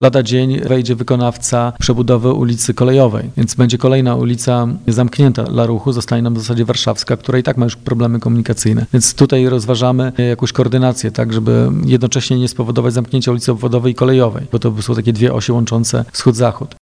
– Kierowcy będą musieli uzbroić się w cierpliwość-  mówi Wojciech Iwaszkiewicz, burmistrz Giżycka.